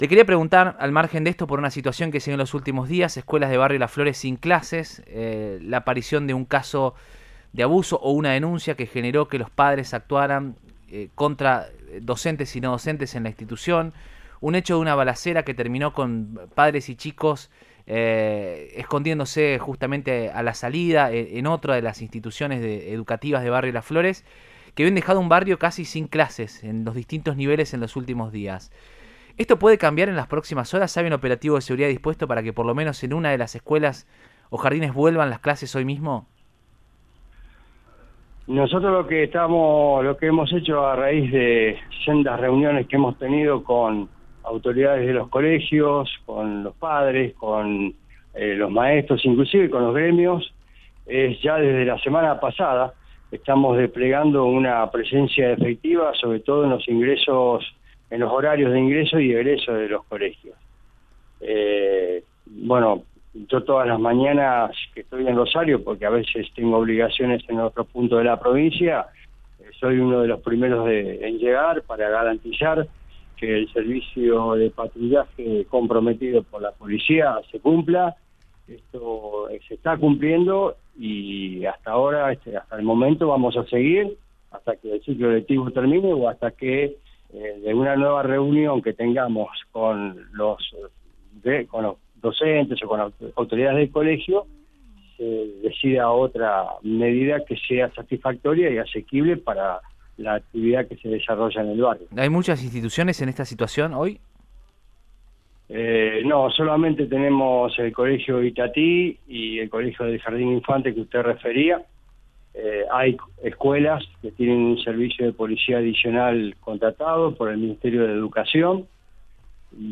“Desde la semana pasada estamos desplegando una presencia efectiva en horarios de ingreso y egreso”, precisó el secretario de Seguridad provincial Claudio Brilloni, en Radioinforme 3, por Cadena 3 Rosario.